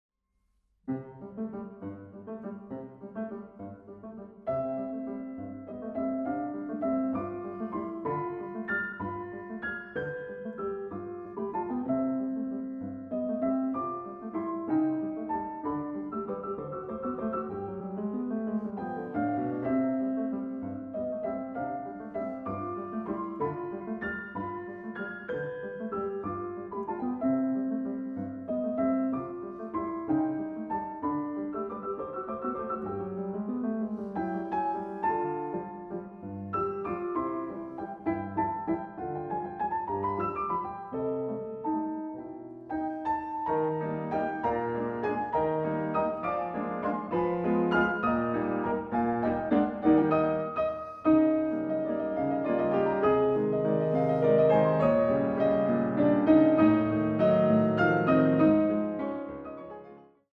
Klavier
Walzer